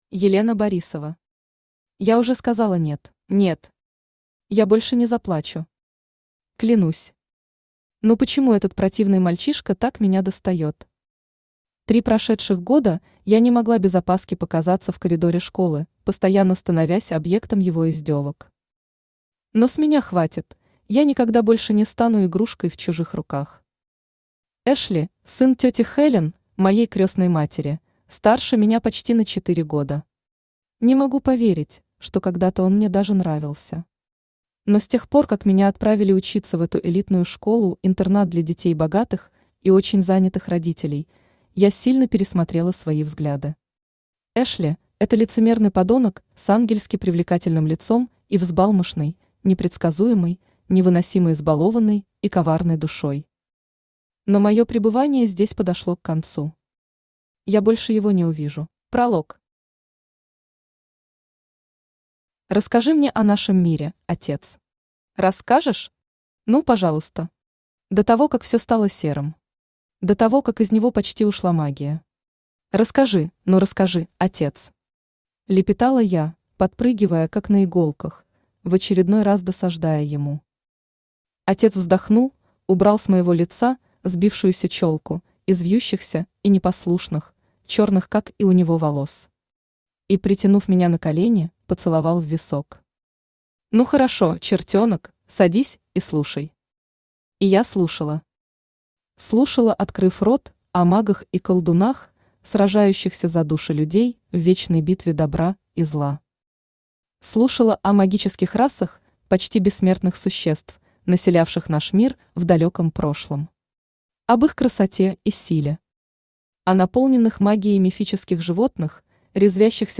Аудиокнига Я уже сказала нет | Библиотека аудиокниг
Aудиокнига Я уже сказала нет Автор Елена Викторовна Борисова Читает аудиокнигу Авточтец ЛитРес.